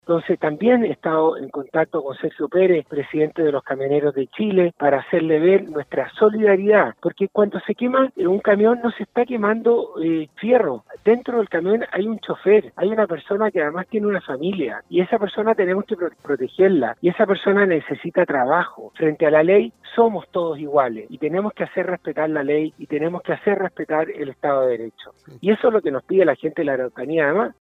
A su paso por la Región de Los Lagos, el Ministro de Agricultura, Antonio Walker, conversó con “Primera Hora” de Radio SAGO, donde destacó la buena relación con el gremio de los camioneros que han permitido mantener la cadena de abastecimiento en pandemia y solidarizó con este sector que se ha visto duramente golpeado por la violencia terrorista en la Araucanía.